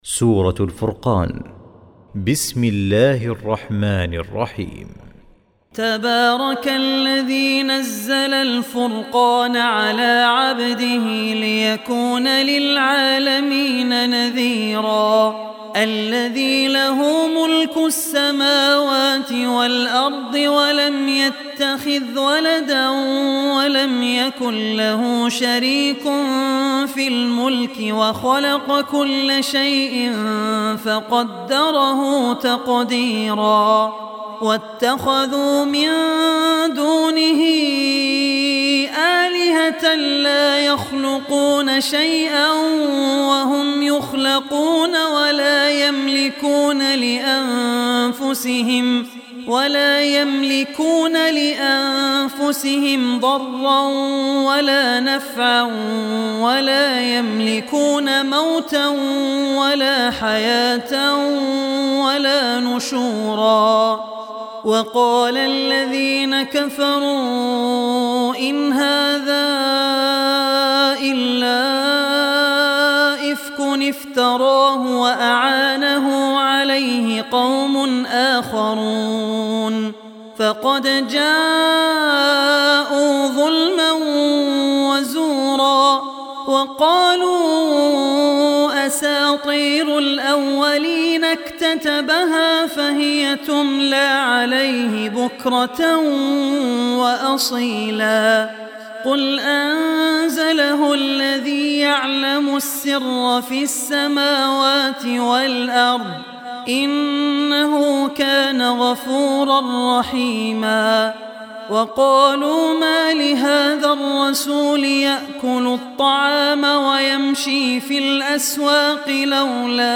Surah Furqan, listen online mp3 tilawat / recitation in Arabic